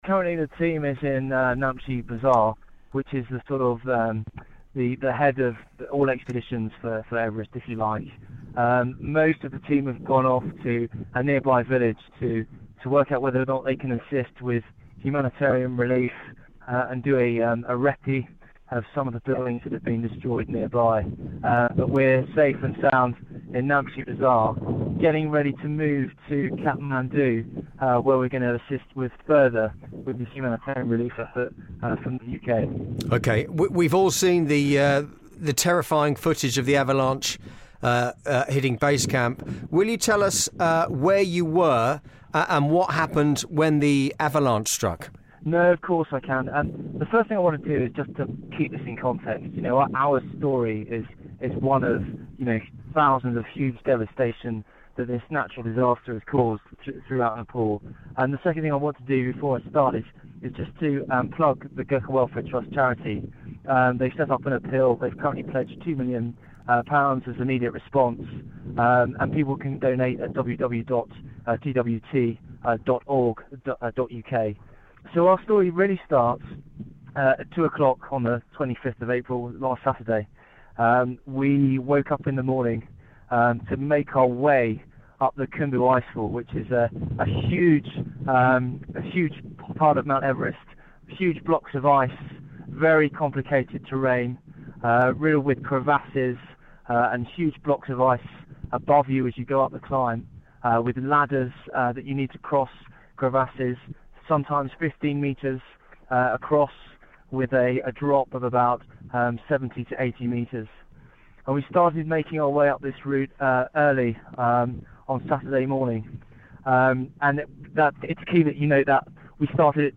They spoke via satellite phone.